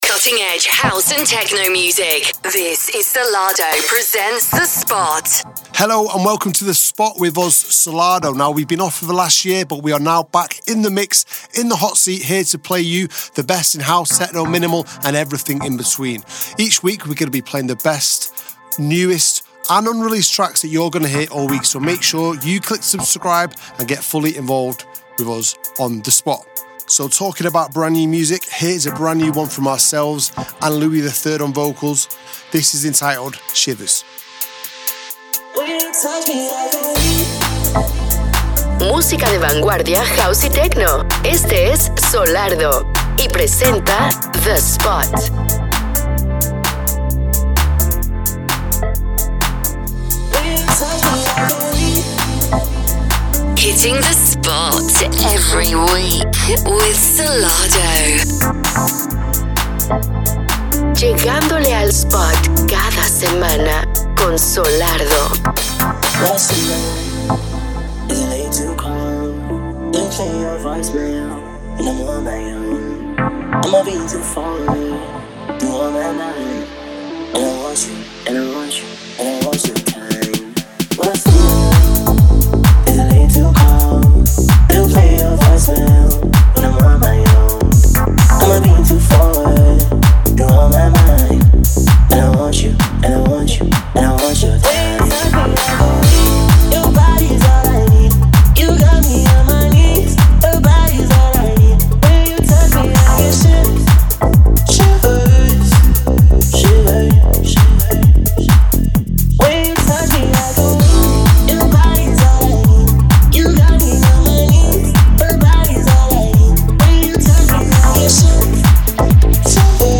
cutting edge house & techno from around the globe
exclusive live mixes and guest appearances